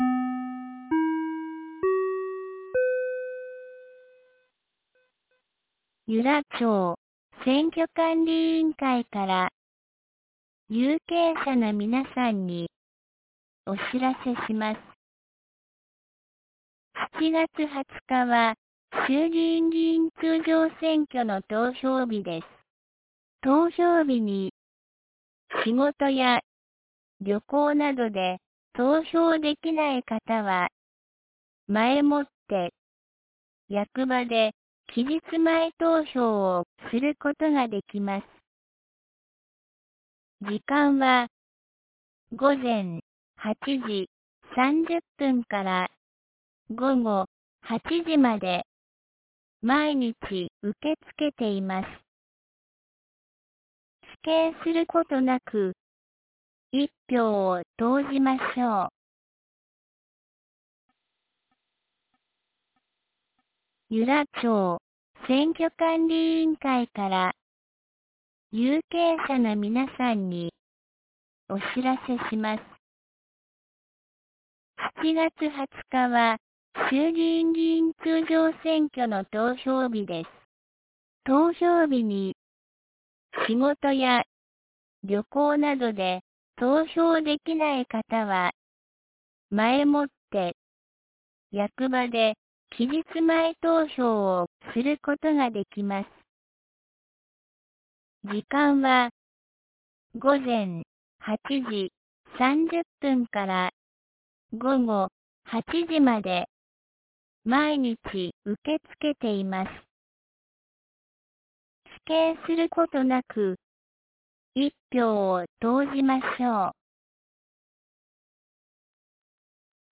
2025年07月04日 07時52分に、由良町から全地区へ放送がありました。